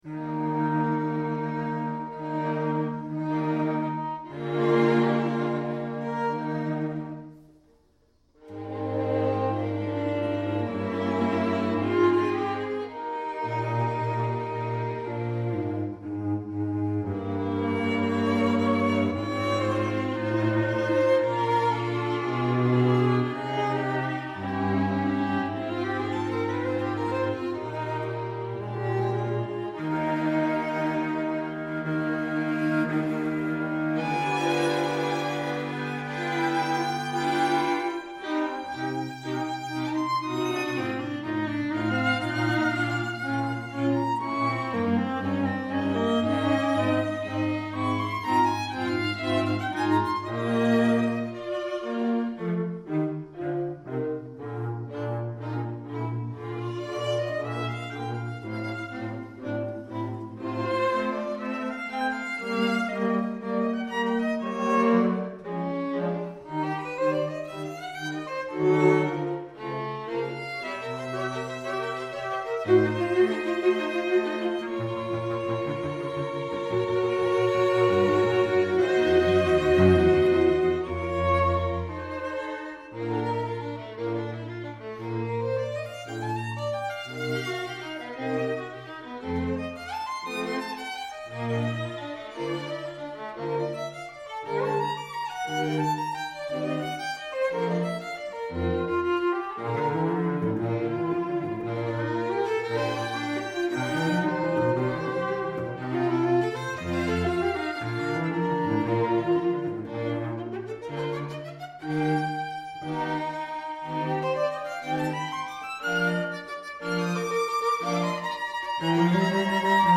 Soundbite 2nd Movt